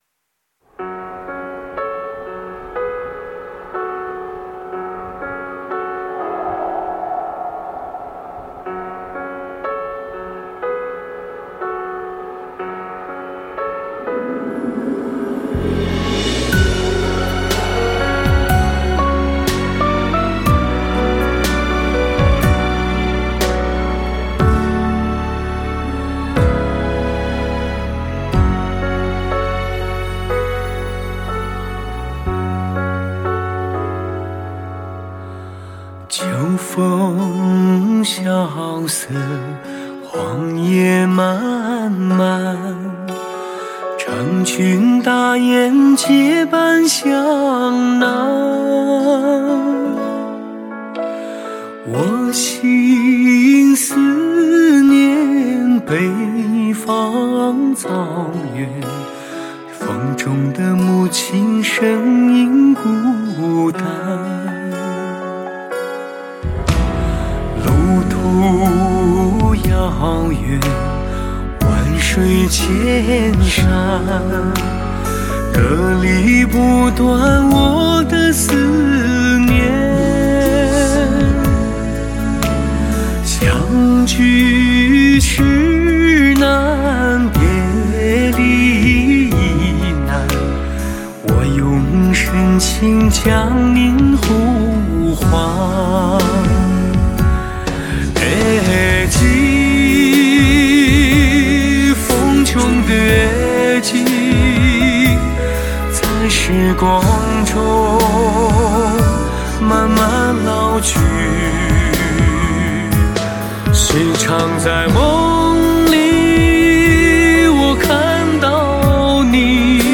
聆听本辑可以从歌曲中领略到草原儿女的那种豪爽的性格夹带着丝丝柔情。